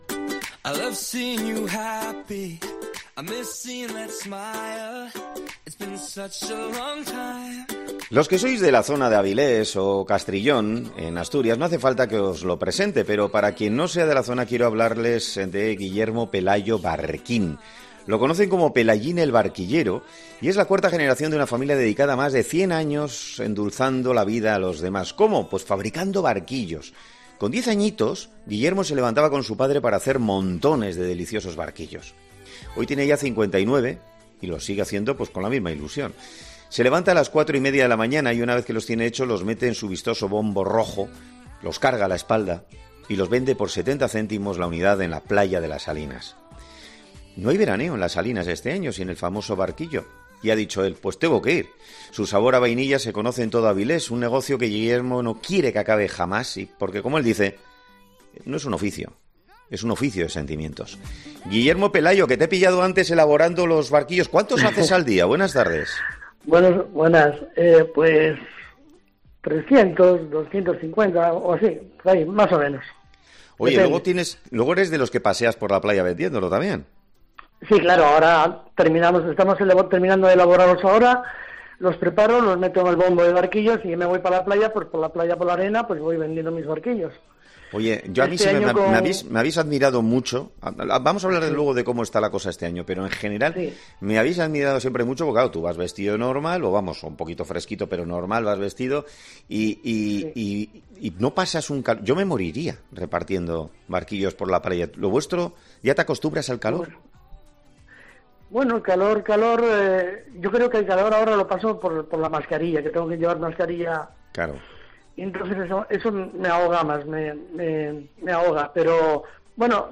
Hoy, en 'Herrera en COPE' hablamos con uno de ellos